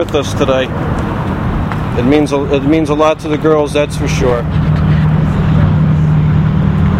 EVP#17 -  Right after you hear me finish talking you can hear a whispered voice.  It sounds like 3 words, with the last word sounding like "us".